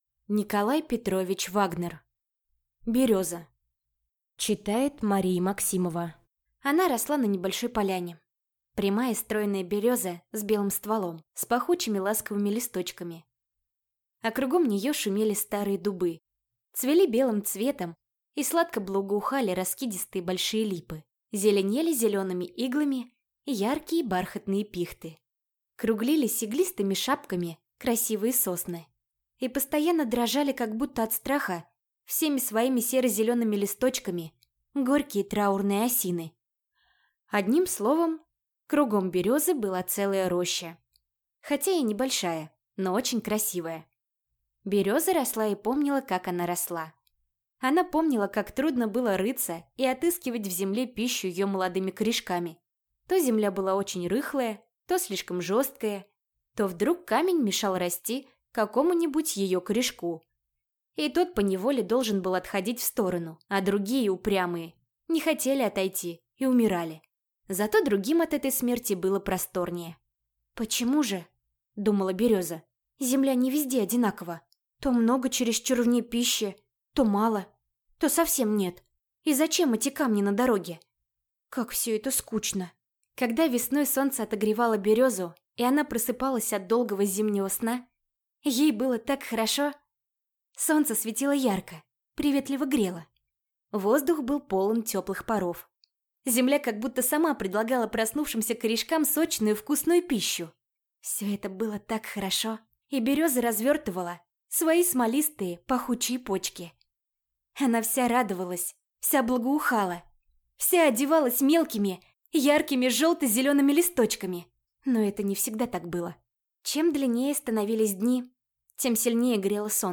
Аудиокнига Береза | Библиотека аудиокниг